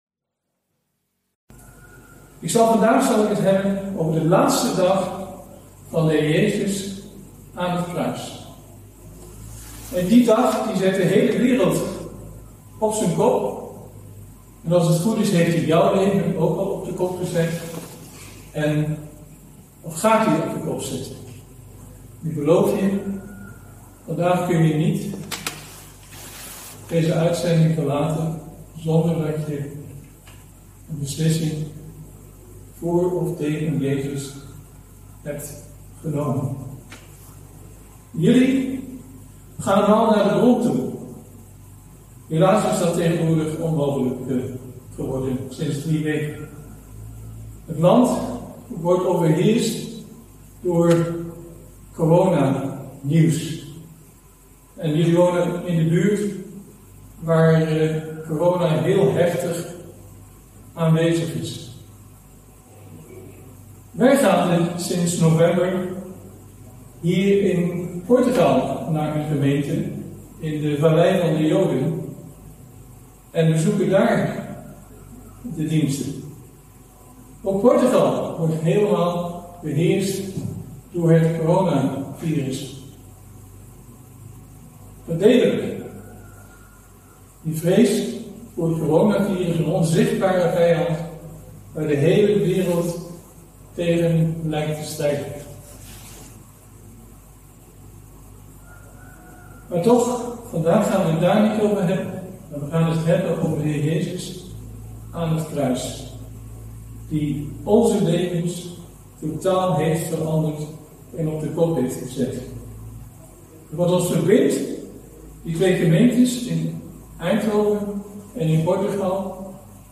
Toespraak 5 april: de Kruisiging - De Bron Eindhoven
derde toespraak onderweg naar Pasen